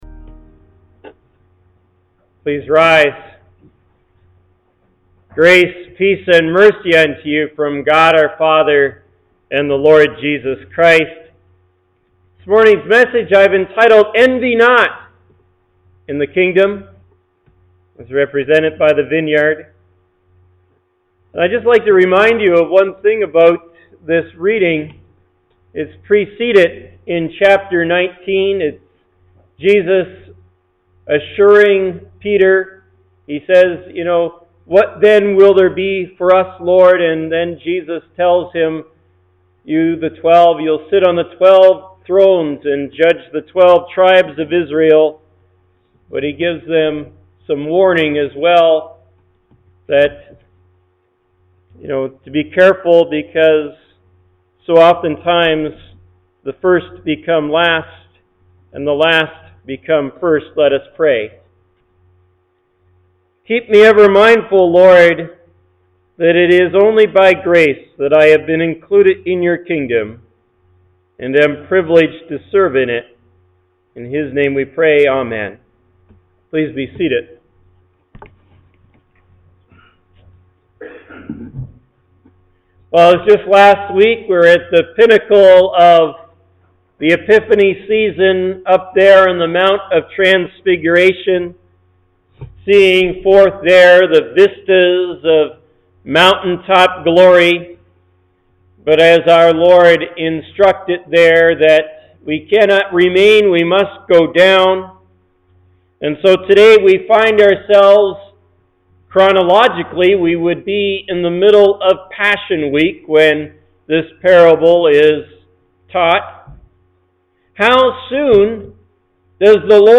Sermon-Archive